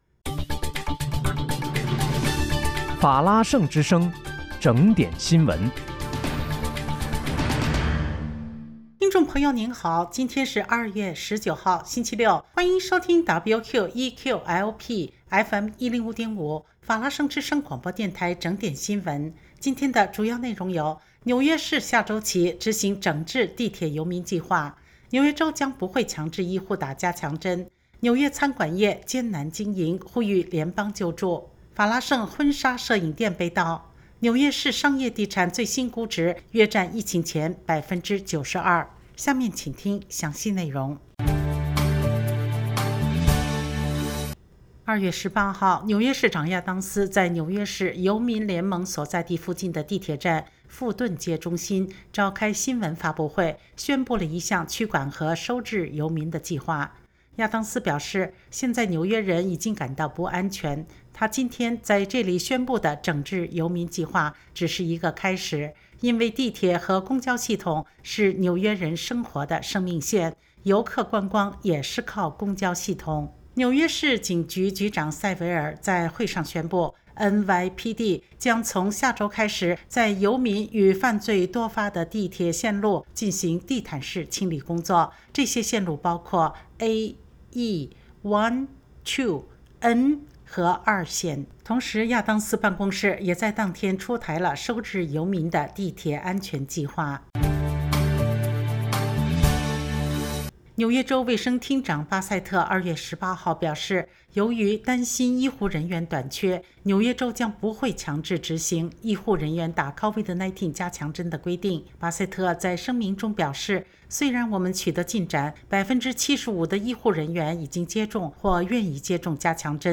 2月19日（星期六）纽约整点新闻
听众朋友您好！今天是2月19号，星期六，欢迎收听WQEQ-LP FM105.5法拉盛之声广播电台整点新闻。